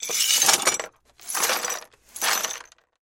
Звуки подметания метлой
Звук подметания метлой осколков стекла от разбитой бутылки